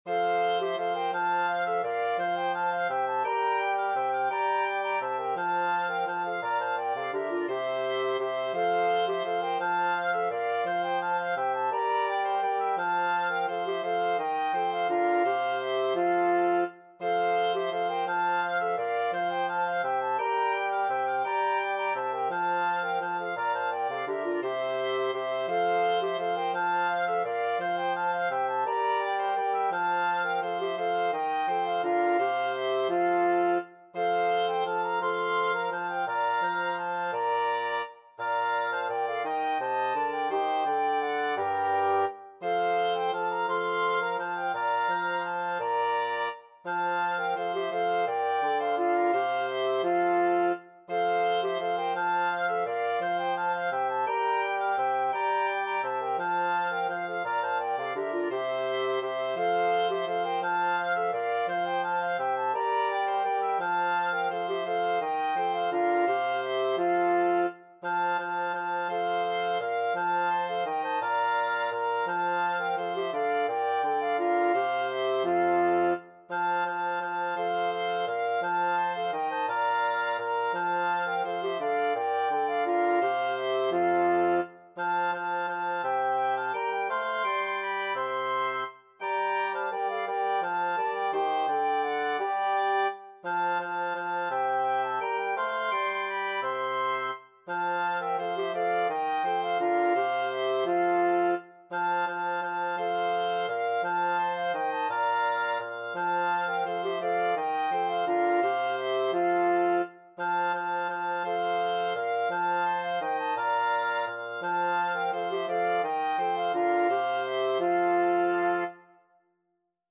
Bergerette Wind Quartet version
FluteOboeClarinetBassoon
F major (Sounding Pitch) (View more F major Music for Wind Quartet )
3/2 (View more 3/2 Music)
Classical (View more Classical Wind Quartet Music)
danserye_2_bergerette_WQT.mp3